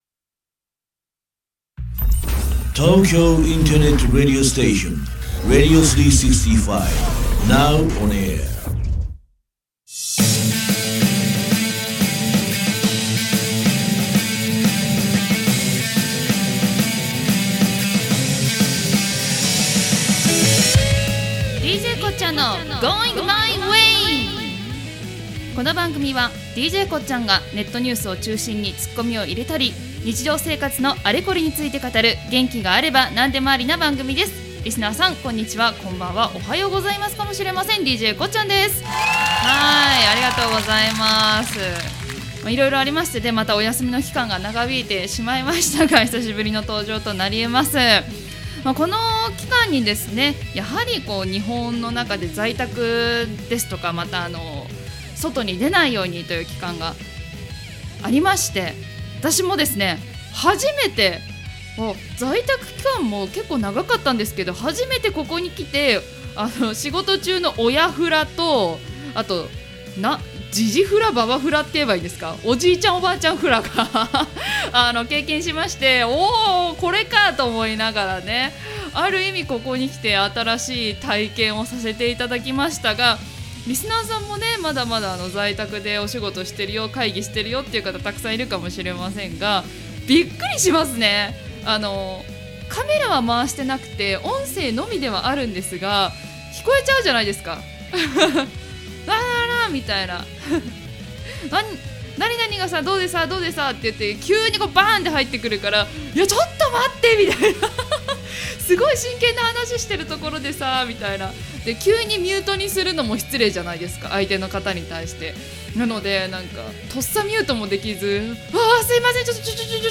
久しぶりの収録にテンション上がってトークが止まらない！？